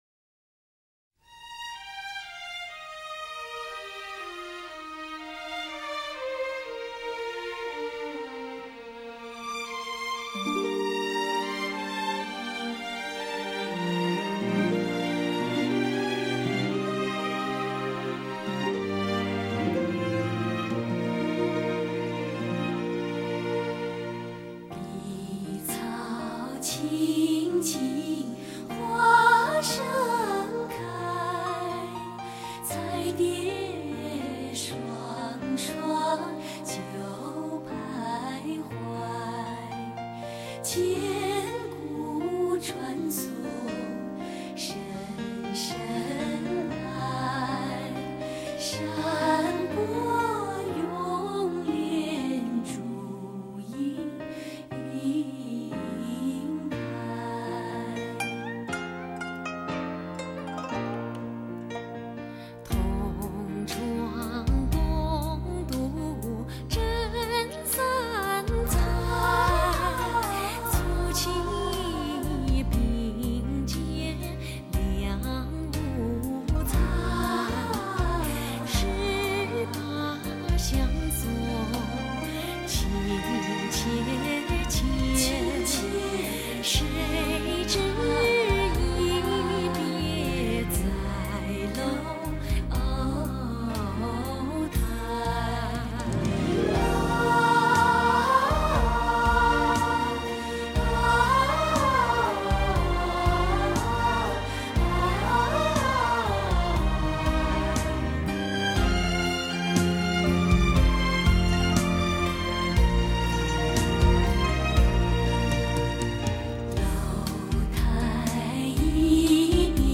收录中国最柔情的声音，穿过浓情的岁月点亮虔诚的心灵，值得购买收藏。